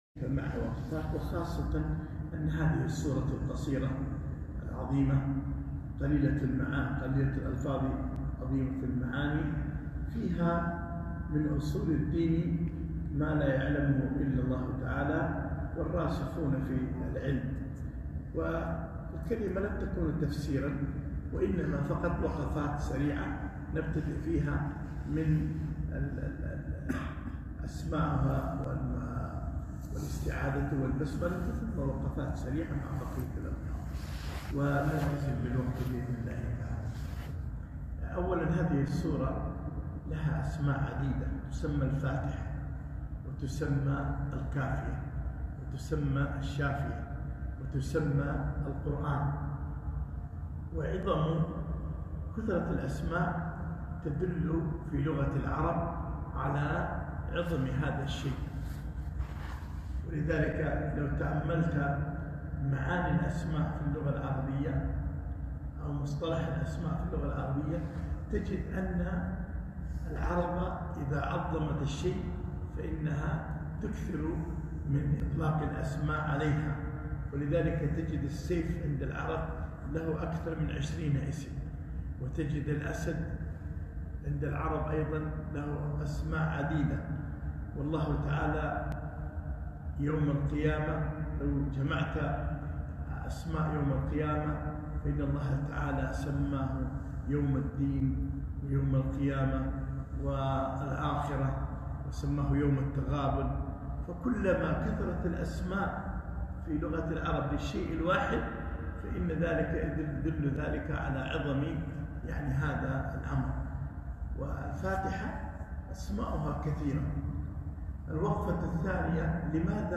كلمة - وقفات من سورة الفاتحة